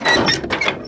geardown.ogg